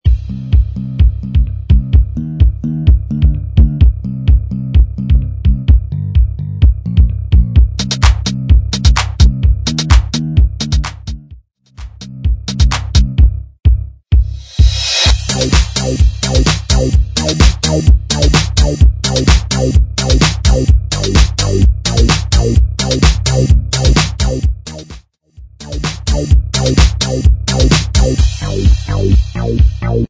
128 BPM
Upbeat Electronic